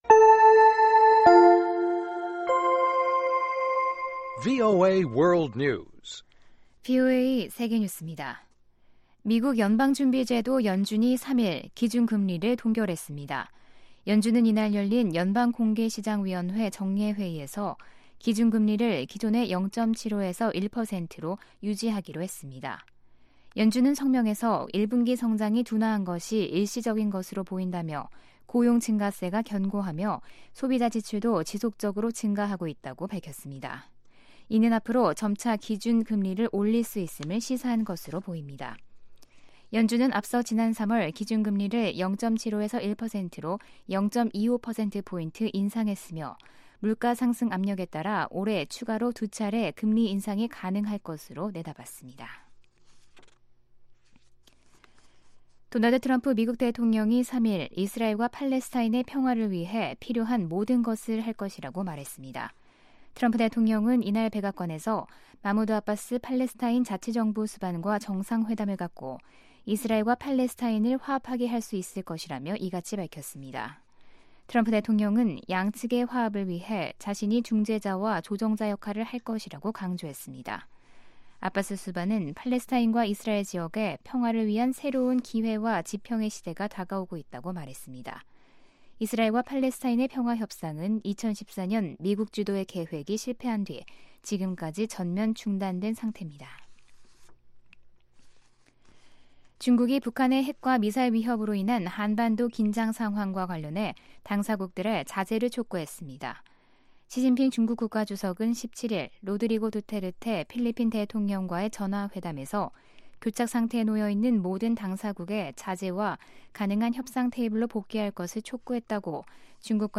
VOA 한국어 방송의 아침 뉴스 프로그램 입니다. 한반도 시간 매일 오전 5:00 부터 6:00 까지, 평양시 오전 4:30 부터 5:30 까지 방송됩니다.